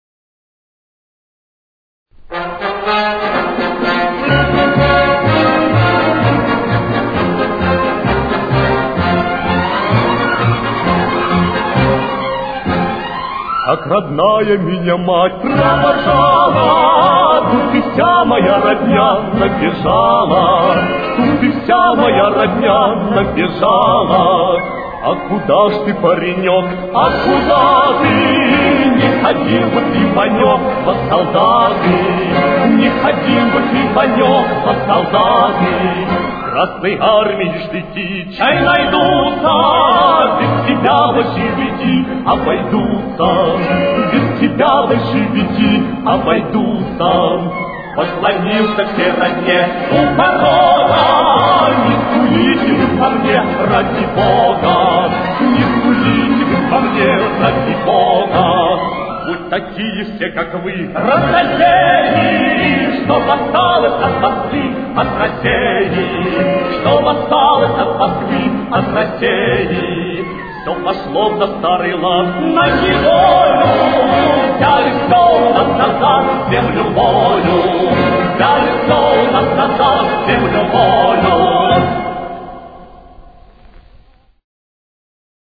с очень низким качеством (16 – 32 кБит/с)
Фа минор. Темп: 130.